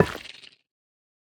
Minecraft Version Minecraft Version latest Latest Release | Latest Snapshot latest / assets / minecraft / sounds / block / sculk_catalyst / place3.ogg Compare With Compare With Latest Release | Latest Snapshot